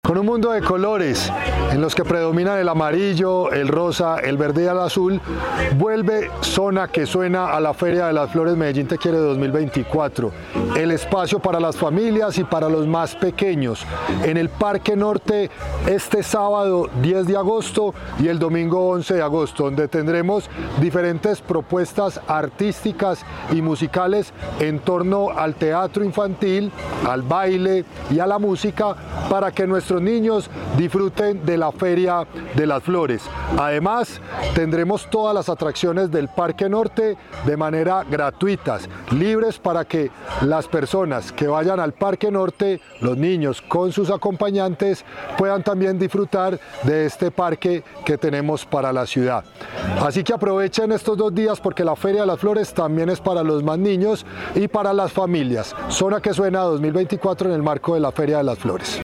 Palabras de Cristian Cartagena, subsecretario de Arte y Cultura Con cuatro escenarios y más de 16 actividades itinerantes por día llegará Zona que Suena a la Feria de las Flores Medellín Te Quiere.